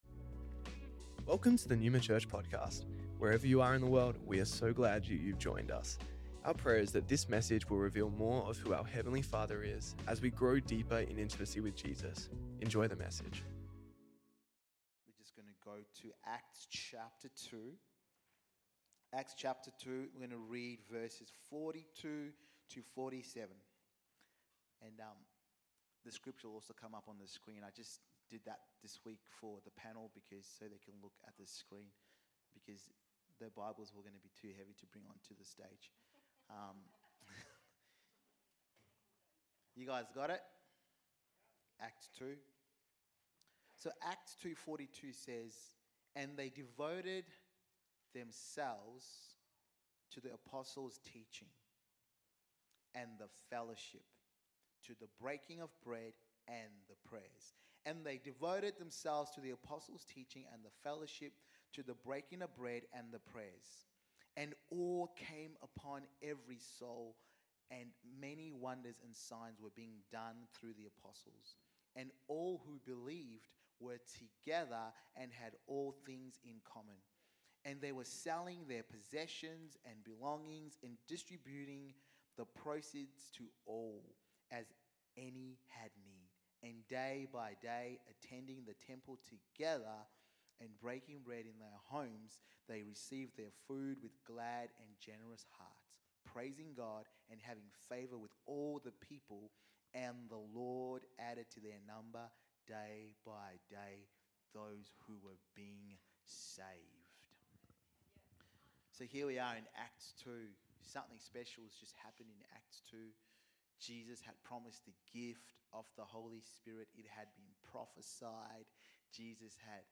Life Group Sunday Panel
Neuma Church Melbourne South Originally Recorded at the 10AM service on Sunday 16th February 2025